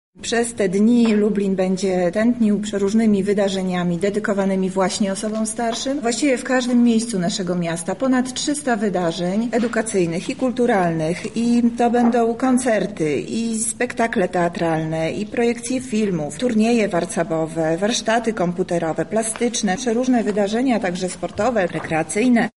– mówi Monika Lipińska, zastępca prezydenta miasta Lublin ds. społecznych.